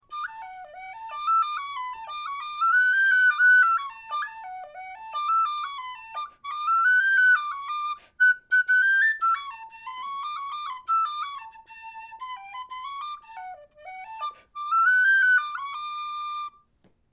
Number: #30 Key: High D Date completed: September 2024 Type: A telescoping brass high D model with brass head and white plastic fipple plug.
Volume: Moderate to loud; sweet with some second-octave chiff.